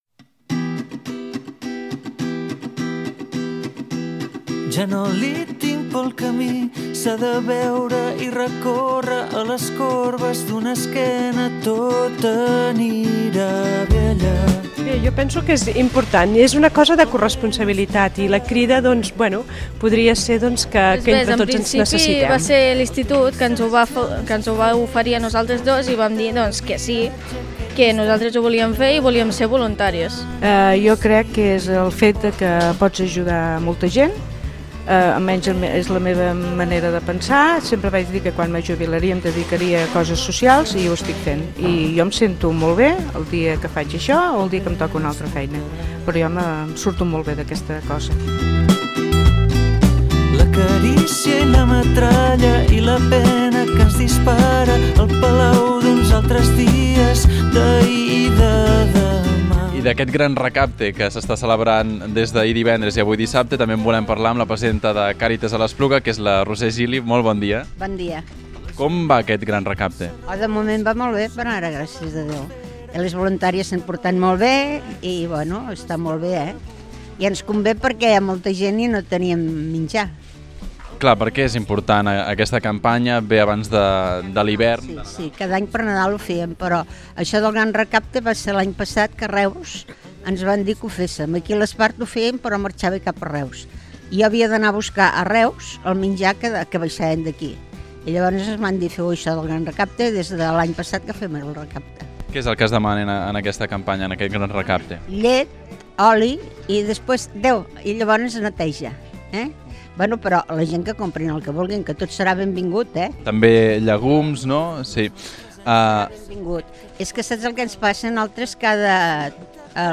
Reportatge-Gran-Recapte-dAliments.mp3